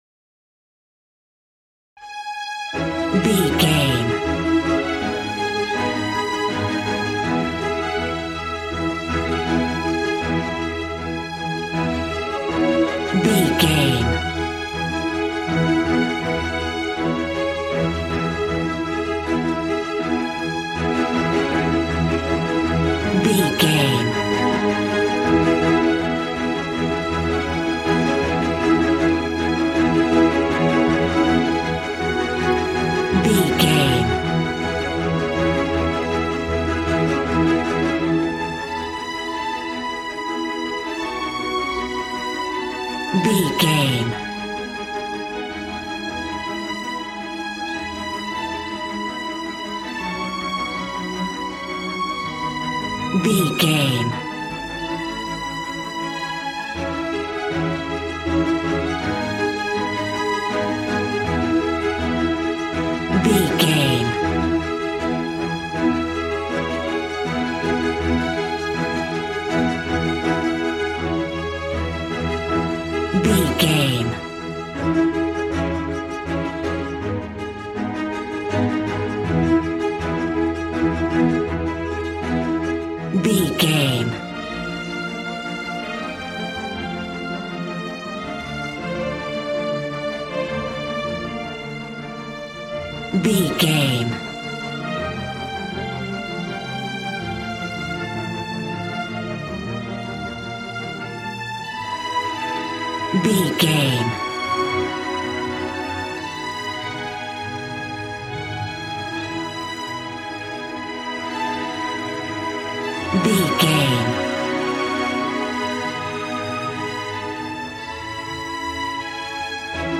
Regal and romantic, a classy piece of classical music.
Ionian/Major
Fast
regal
strings
brass